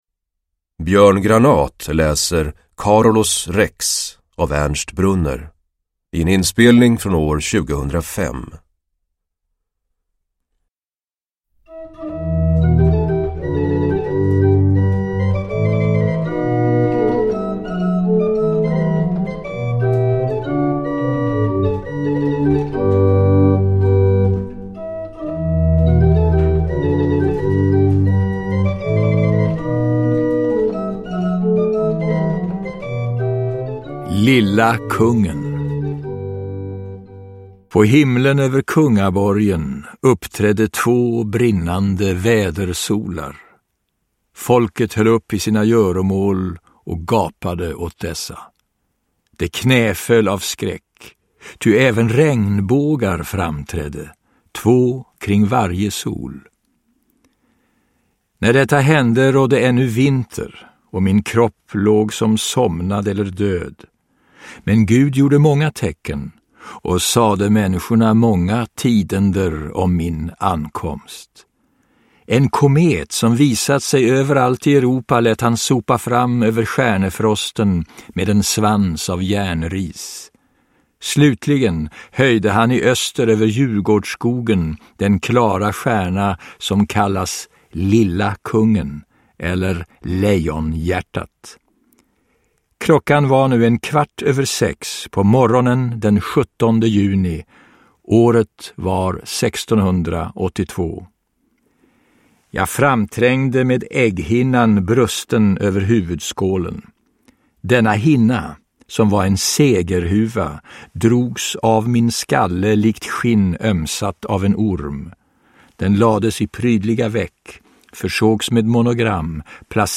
Uppläsare: Björn Granath
Ljudbok